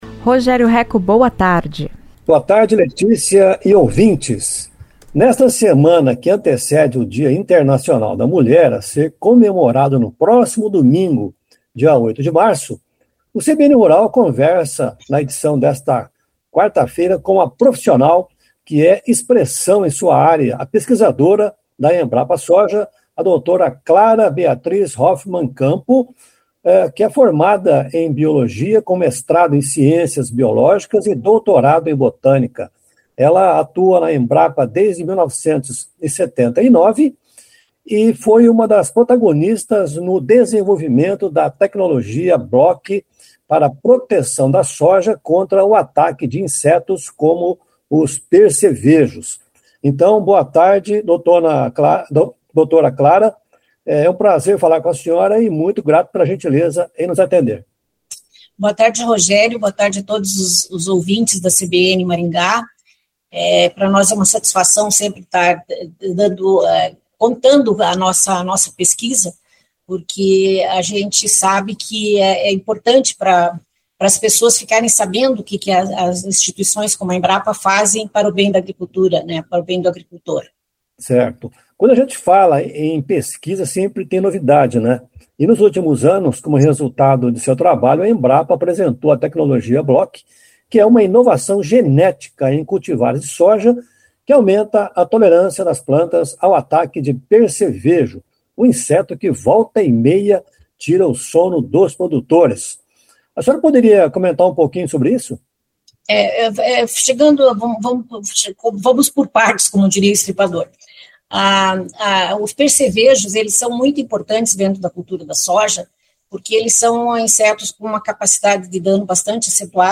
Pesquisadora da Embrapa fala sobre o controle do percevejo na soja